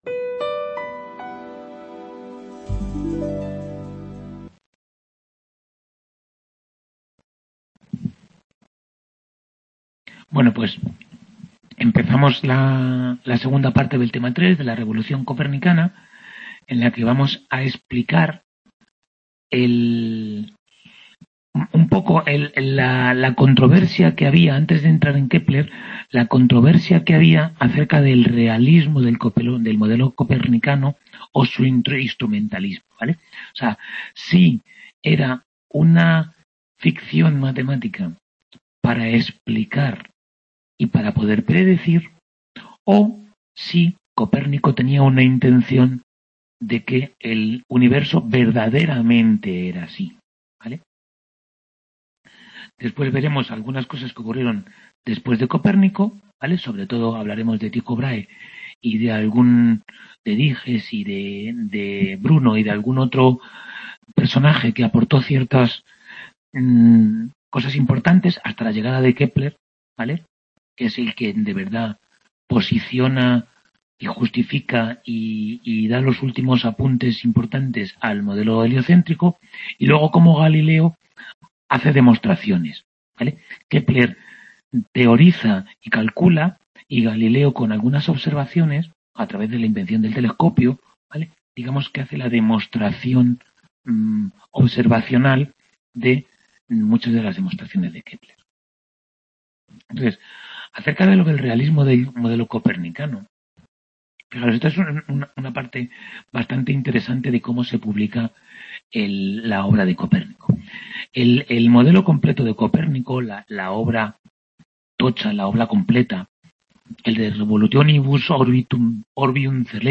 Tutoría 7b de Historia General de la Ciencia I | Repositorio Digital